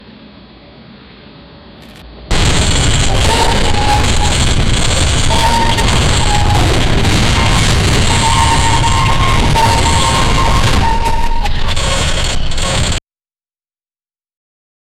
archer-sound-battle-of-ar-rsosfxix.wav